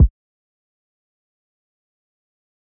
JJ_Kick2.wav